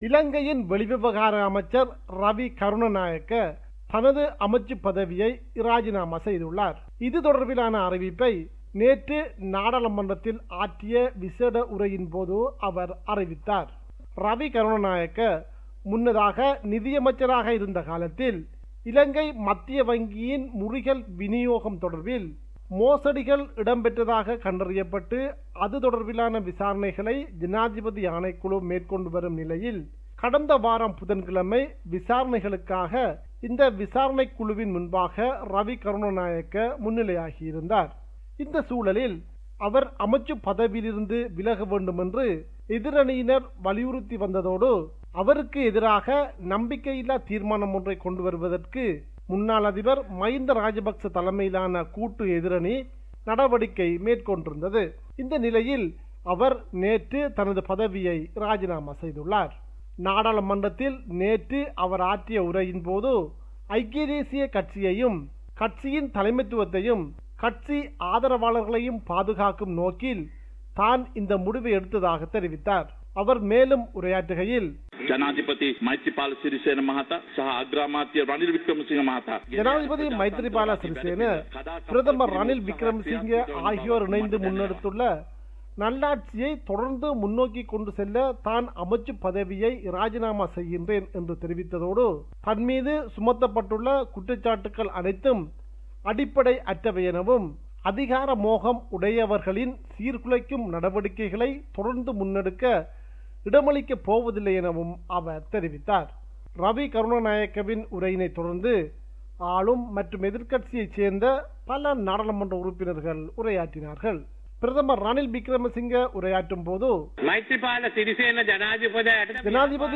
Tamil News